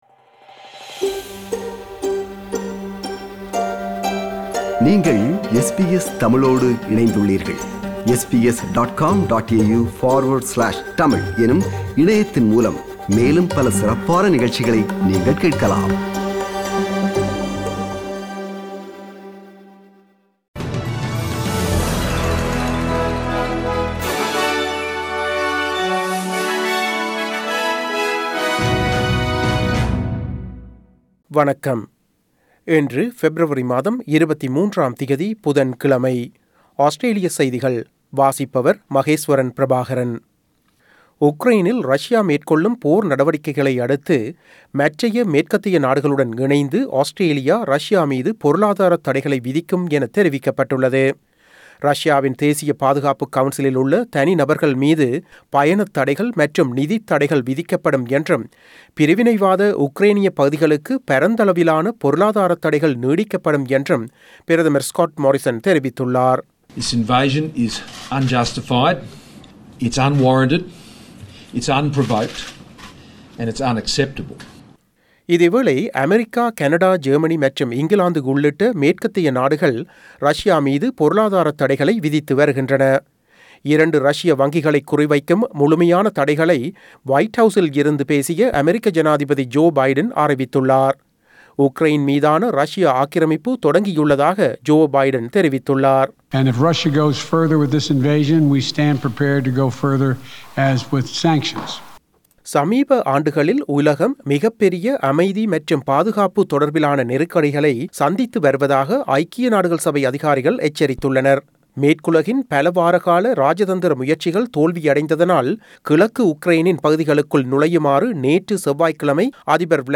Australian news bulletin for Wednesday 23 February 2022.